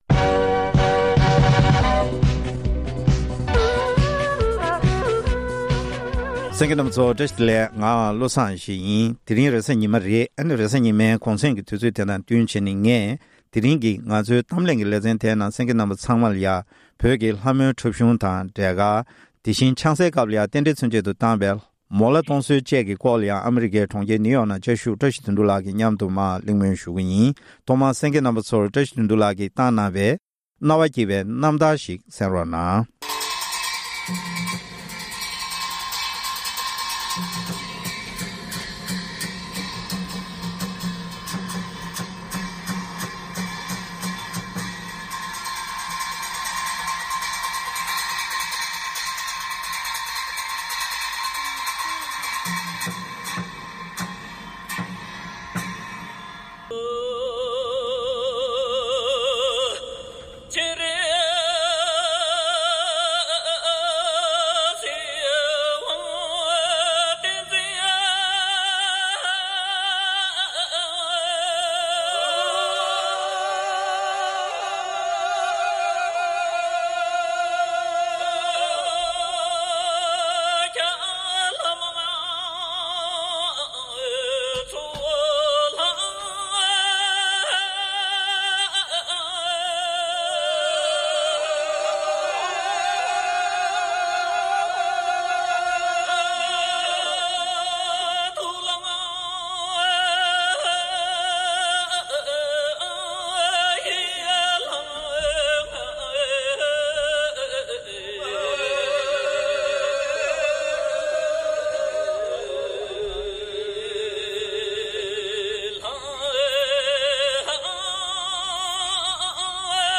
གླེང་མོལ་ཞུས་པ་དེར་གསན་རོགས